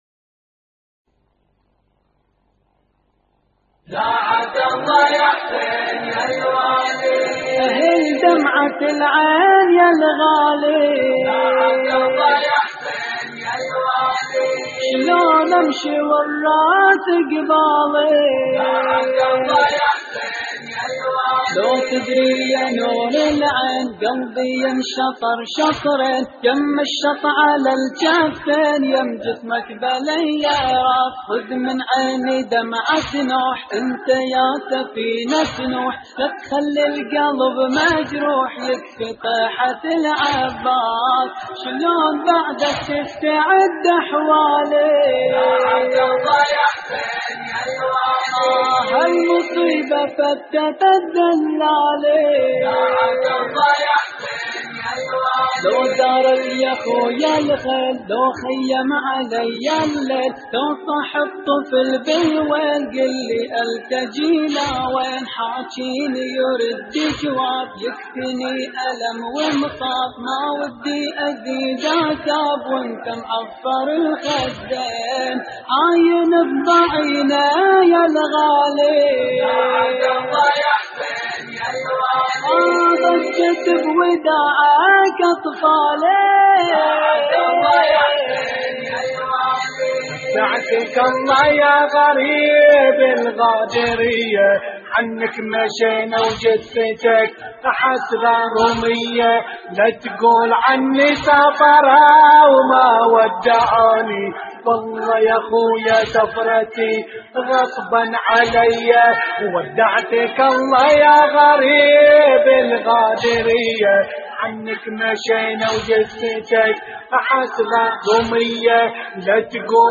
اللطميات الحسينية
استديو «الظليمة»